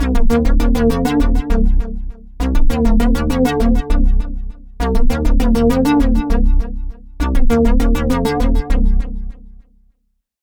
Synth_Element.ogg